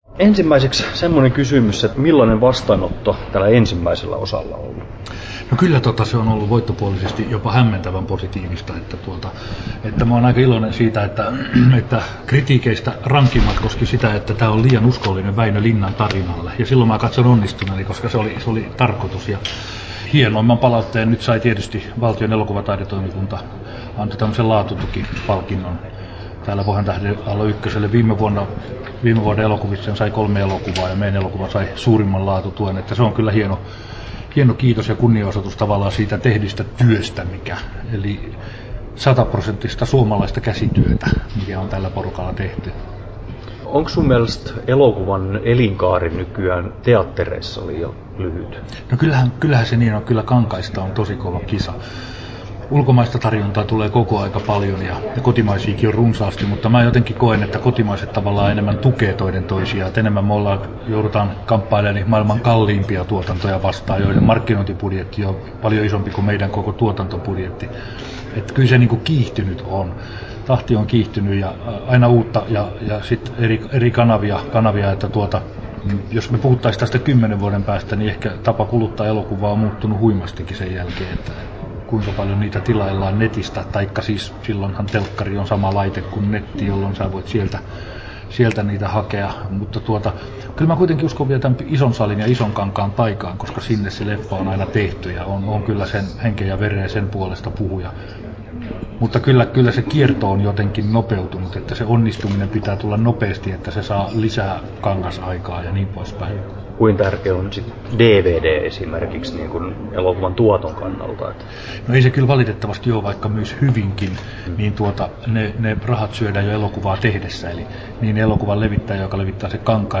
Timo Koivusalon haastattelu Kesto: 11'10" Tallennettu: 21.9.2010, Turku Toimittaja